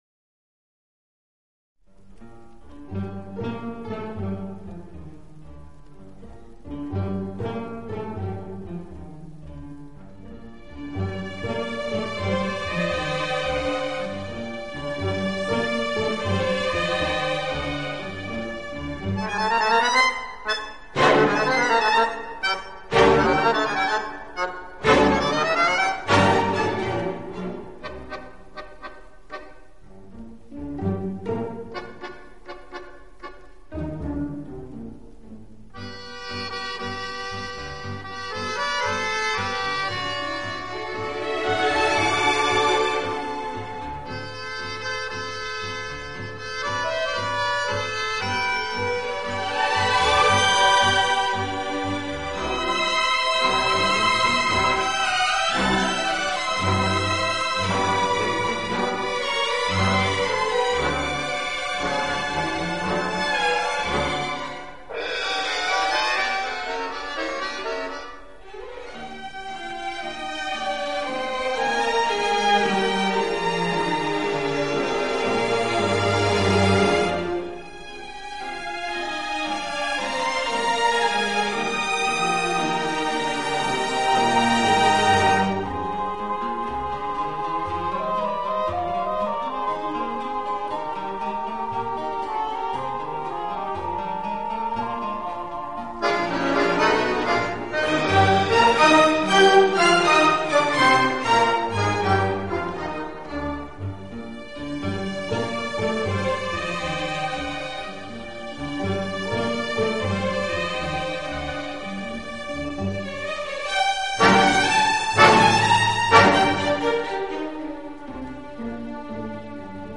一波一浪的小提琴，静若花落水面，动如飞瀑流泻。
这个乐团的演奏风格流畅舒展，
旋律优美、动听，音响华丽丰满。